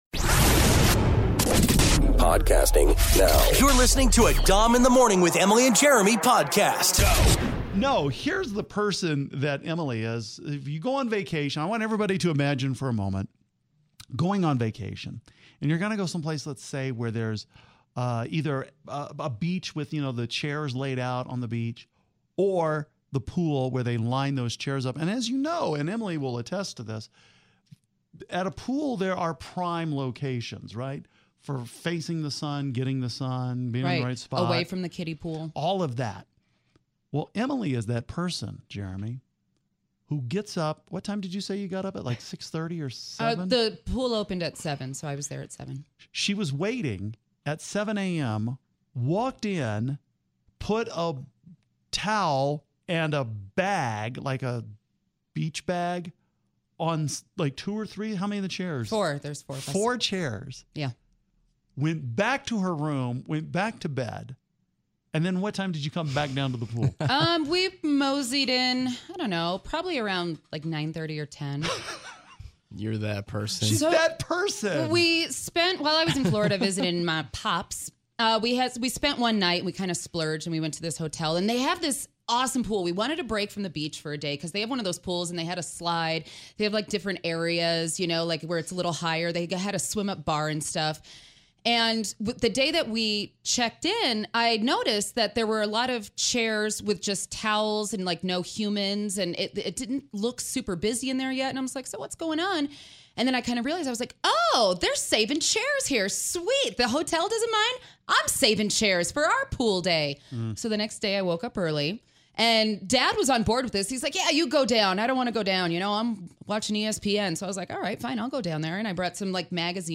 Another hard hitting 5 calls says it all topic. Saving pool chairs.